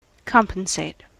En-us-compensate.spx